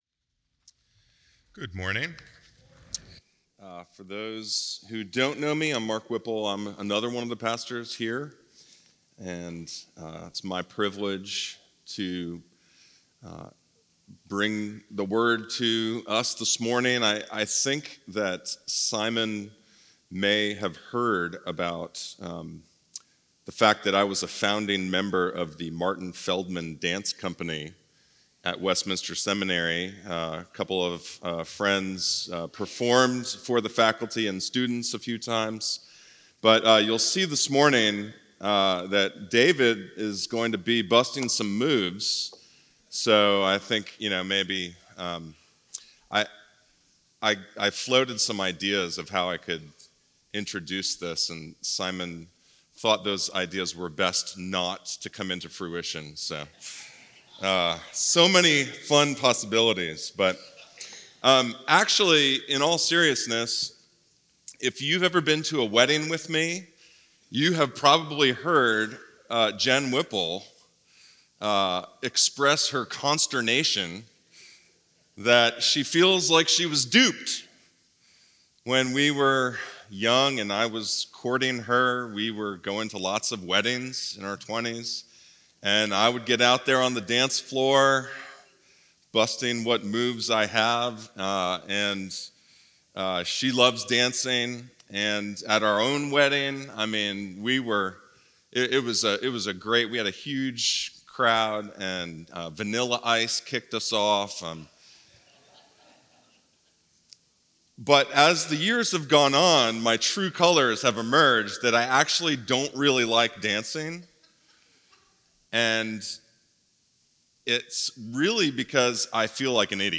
CGS-Service-3-30-25-Audio-Podcast.mp3